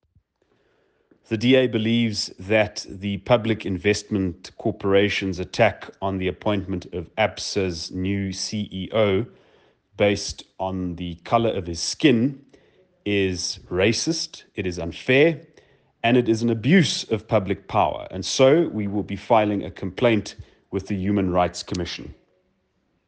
English and Afrikaans soundbites by Cilliers Brink MP.